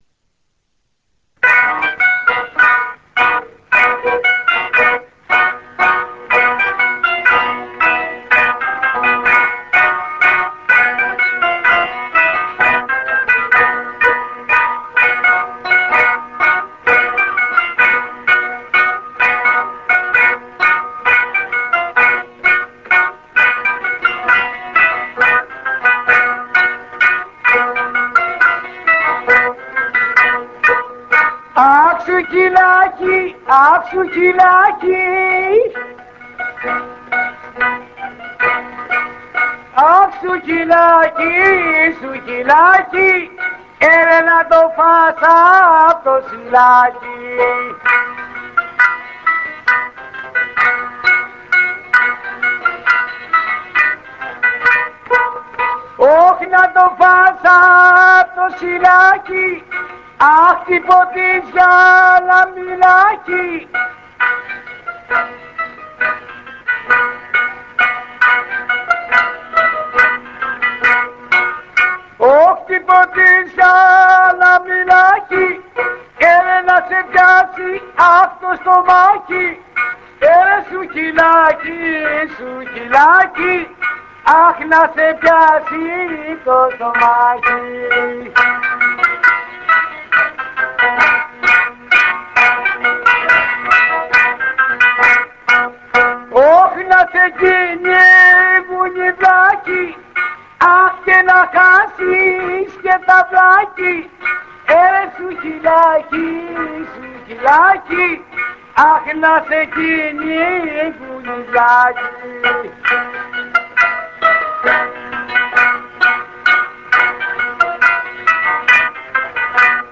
Forbidden rebetiko